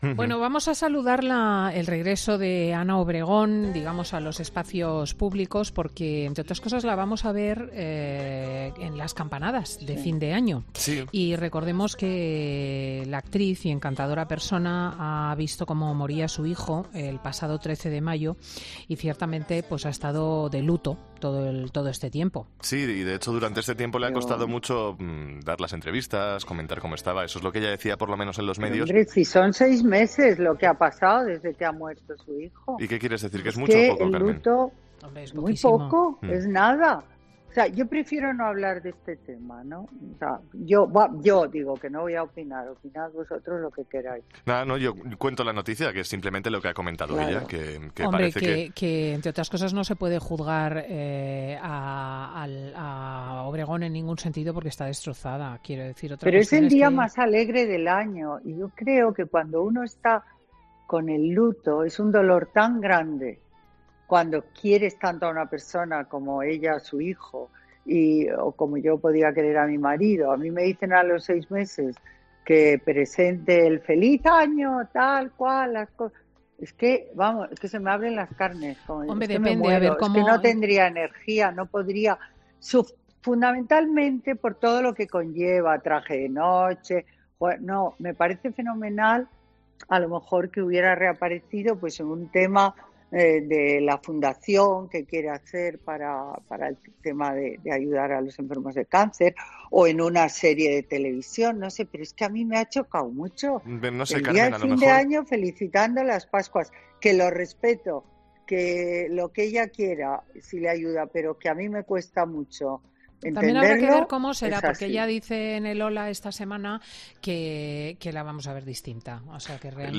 La colaboradora de Fin de Semana, Carmen Lomana, comentaba con Cristina el regreso de Ana Obregón a TVE tras la muerte de su hijo, Álex Lequio
La socialité Carmen Lomana sorprendía este sábado en los micrófonos de Fin de Semana de COPE con un reproche a la vuelta de la actriz Ana Obregón a las cámaras de TVE con motivos de las Campanadas de Nochevieja que darán punto y final a este 2020.